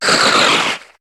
Cri de Stalgamin dans Pokémon HOME.